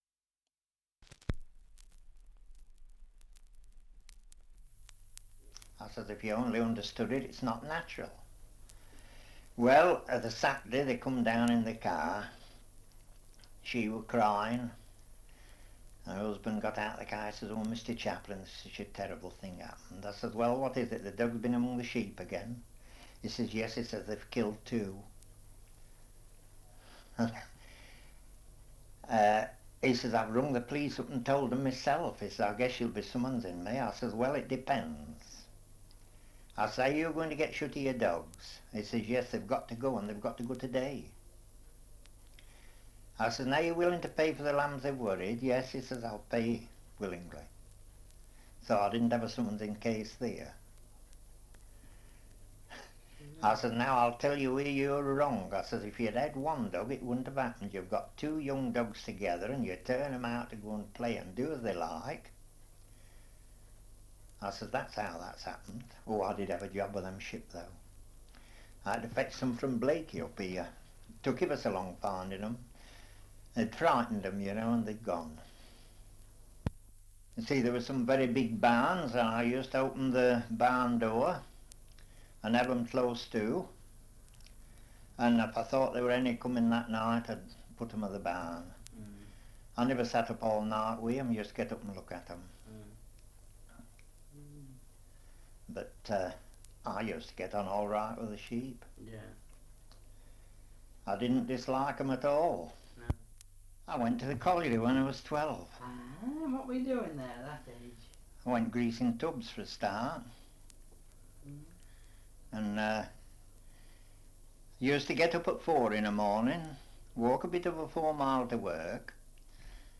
Survey of English Dialects recording in Markfield, Leicestershire
78 r.p.m., cellulose nitrate on aluminium